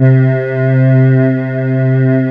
Index of /90_sSampleCDs/USB Soundscan vol.28 - Choir Acoustic & Synth [AKAI] 1CD/Partition D/14-AH VOXST